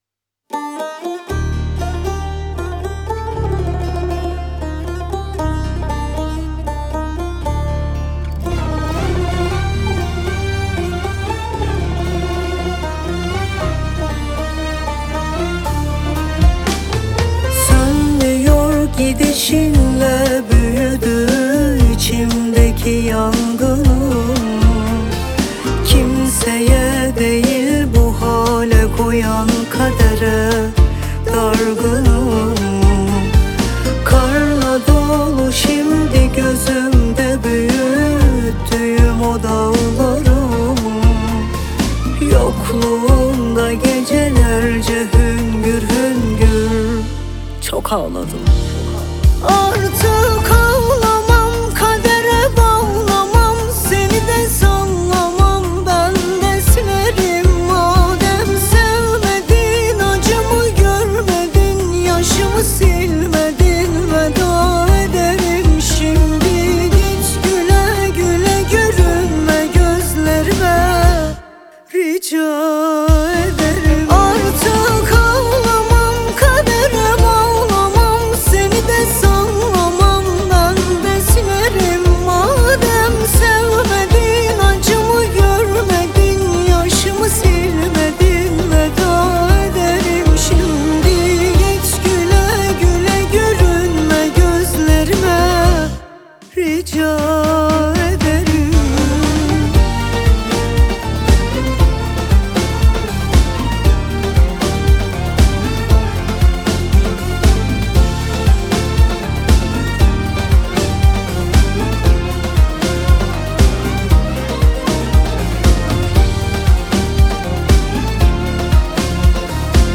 آهنگ ترکیه ای آهنگ غمگین ترکیه ای آهنگ هیت ترکیه ای